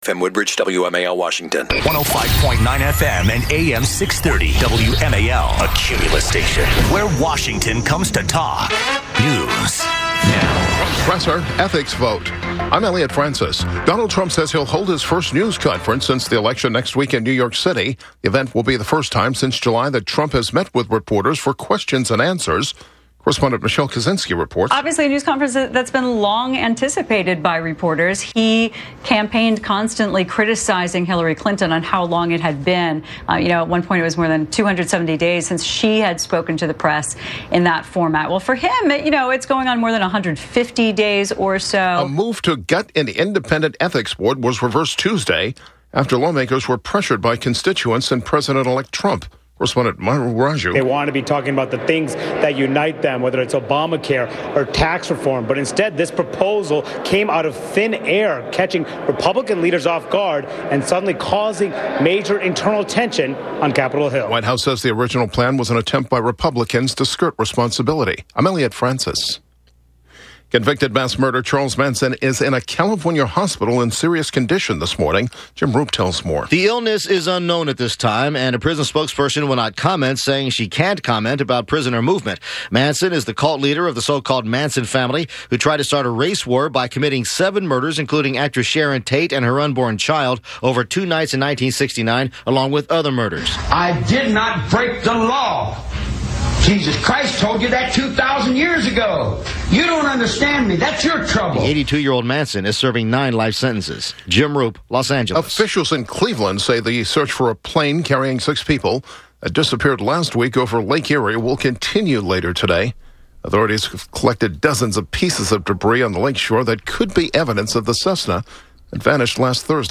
Cybersecurity expert
guest host